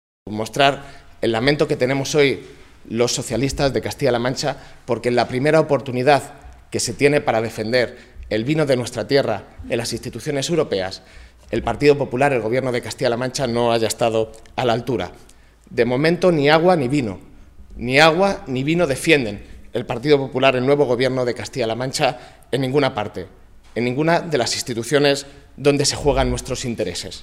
Sergio Gutiérrez, eurodiputado del PSOE por Castilla-La Mancha.
Cortes de audio de la rueda de prensa